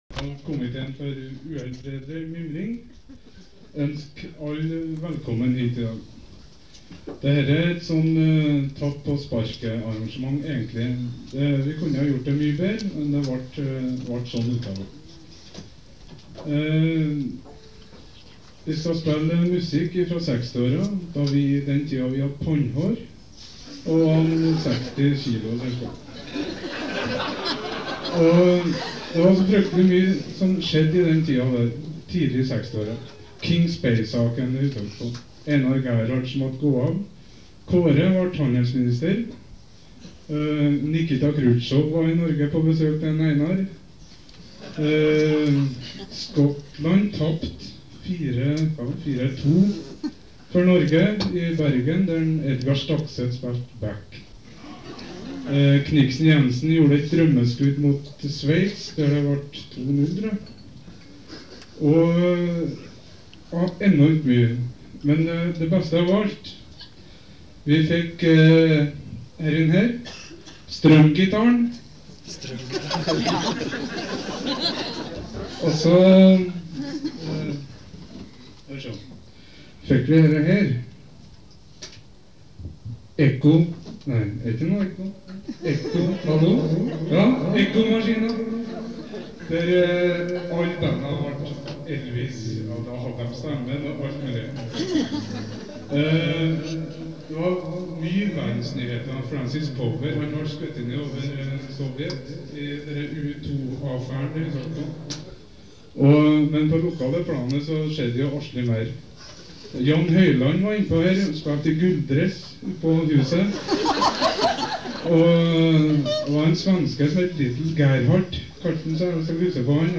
Mimreaften i Steinkjersalen på Grand Hotel